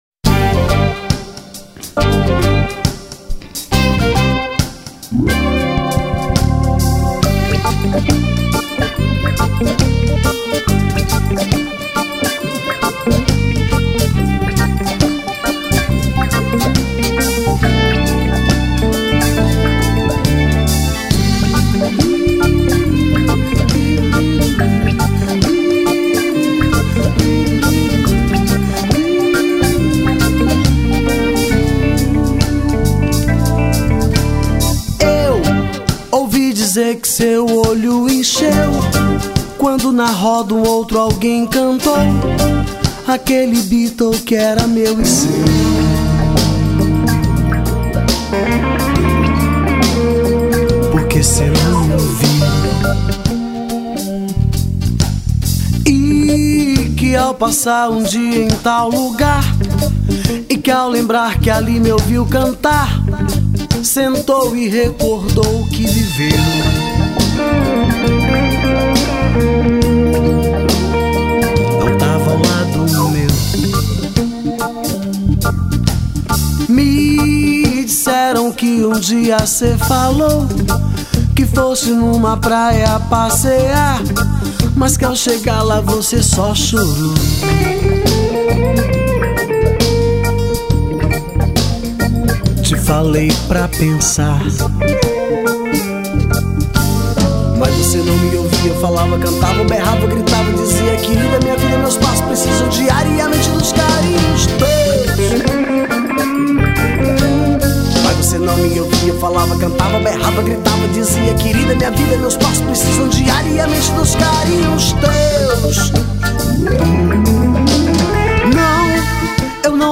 2963   04:25:00   Faixa: 6    Rock Nacional